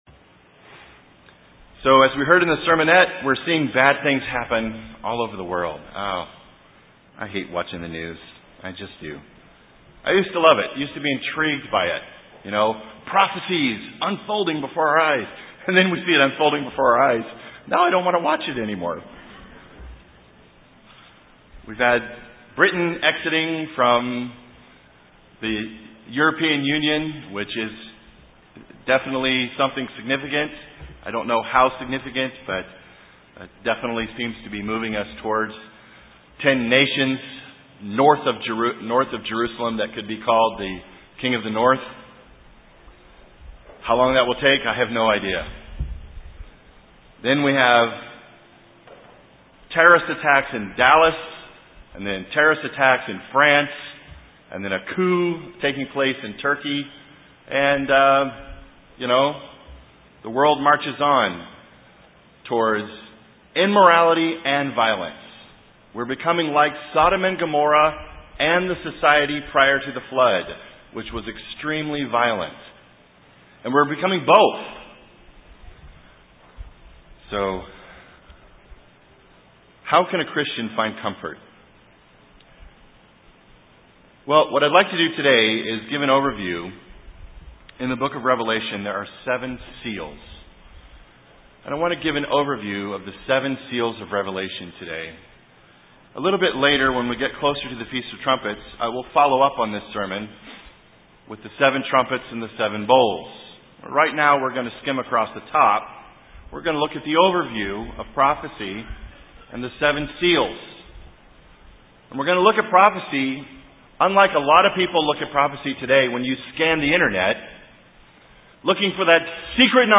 This sermon looks at the seven seals in Revelation and parallels it with the Sermon on the Mount in Matthew 24.